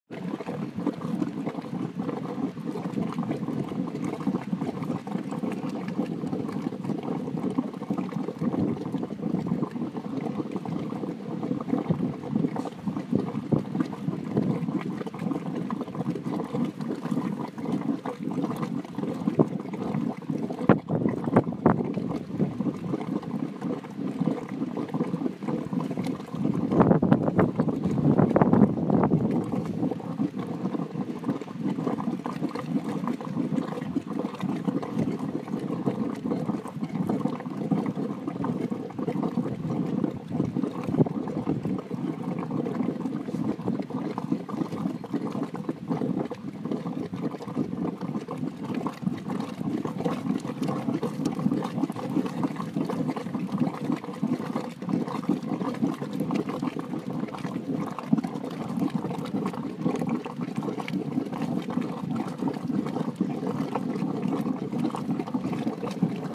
Bubbling hot spring, Gamla Laugin lagoon, Iceland